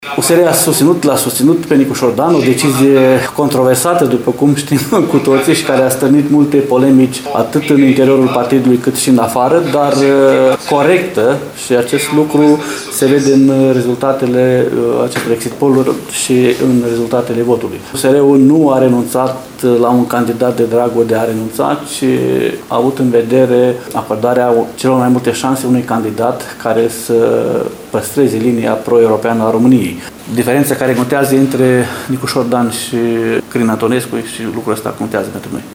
AUDIO / Declarații după primul tur al prezidențialelor: bucurie la AUR Suceava, speranțe în rest